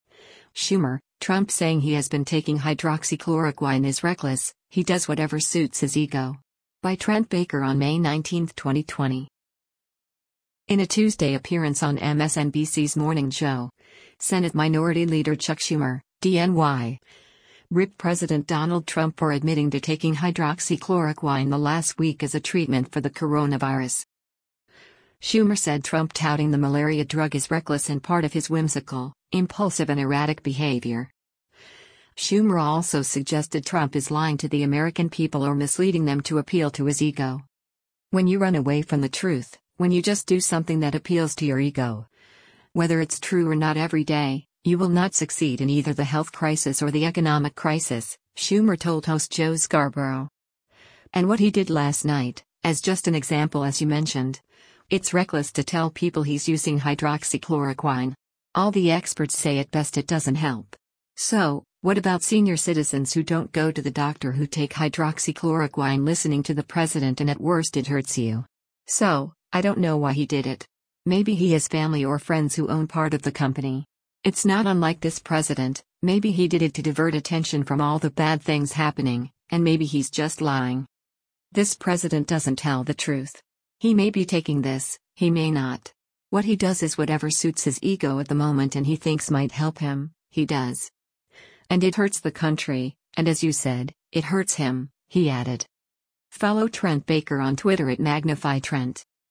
In a Tuesday appearance on MSNBC’s “Morning Joe,” Senate Minority Leader Chuck Schumer (D-NY) ripped President Donald Trump for admitting to taking hydroxychloroquine the last week as a treatment for the coronavirus.